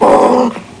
scary
monster.mp3